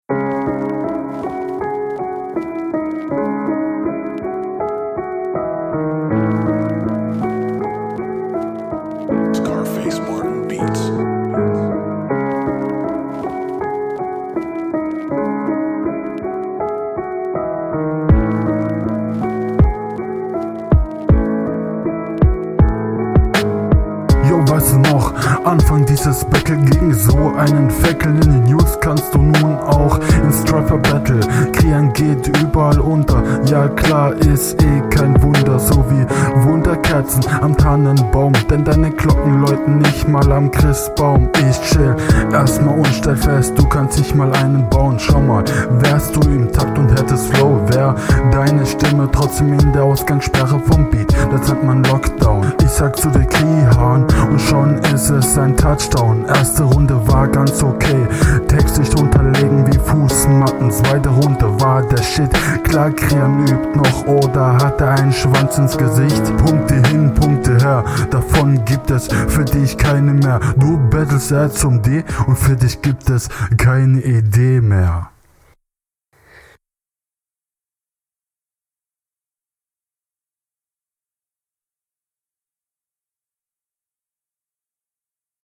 Beat ist nice.